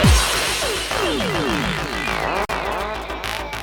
Scratches are audible all along, and the drop-out is very audible in the middle of the sample.
According to the DAT file, the error rate is about 22,000 per second.